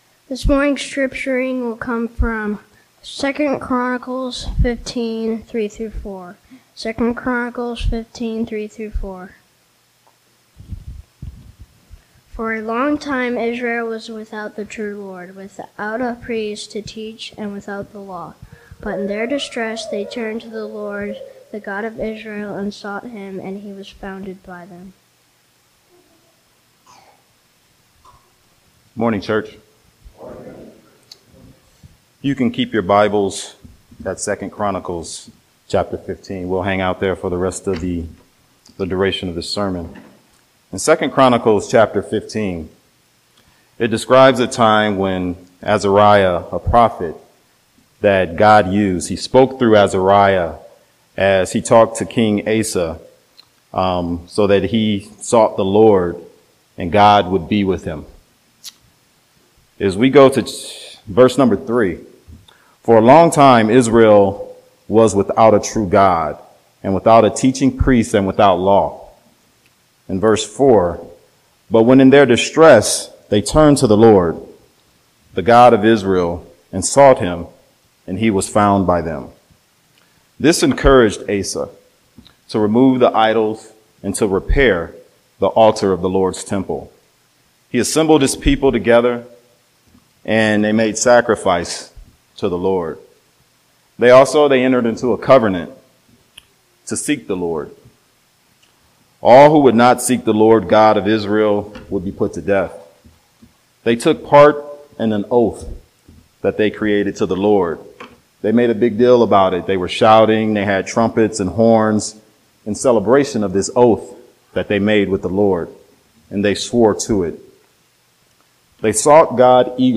2 Chronicles 12:3-4 Service: Sunday Morning Topics